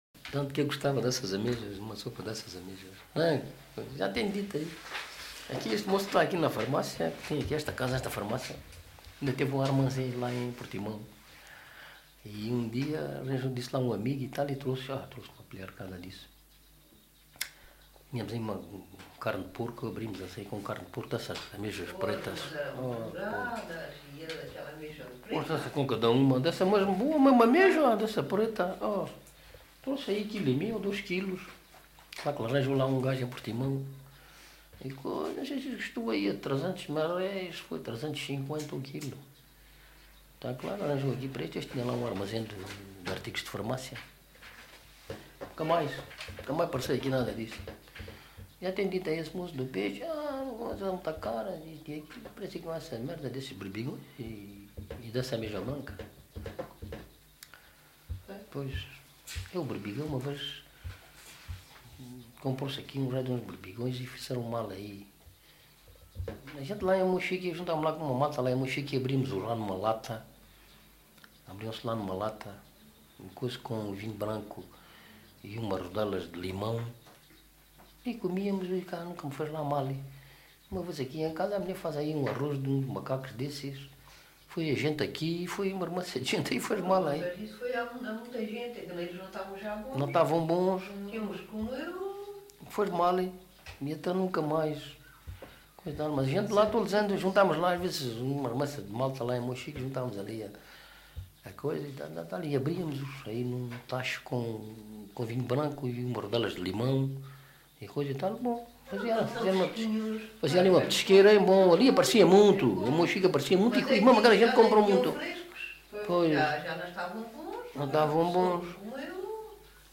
LocalidadeMontes Velhos (Aljustrel, Beja)